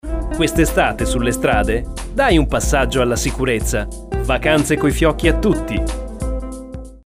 Sprecher italienisch.
Sprechprobe: Werbung (Muttersprache):